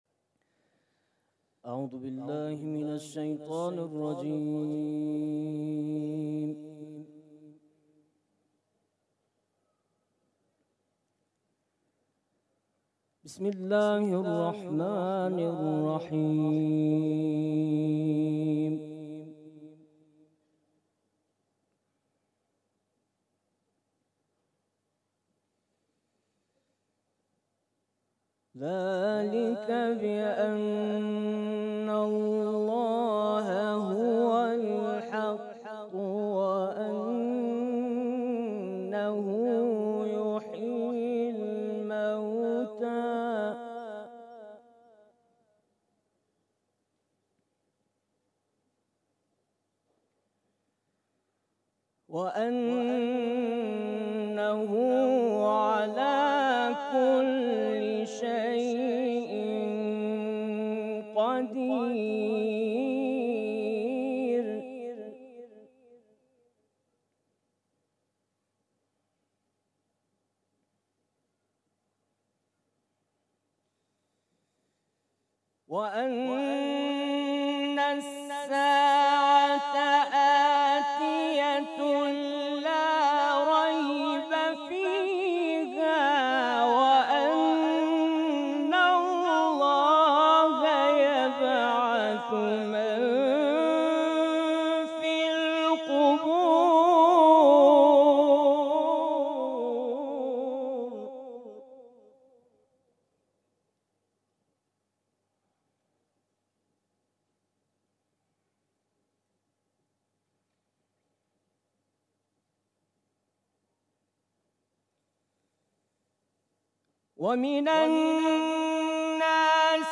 قرائت
مسابقات سراسری قرآن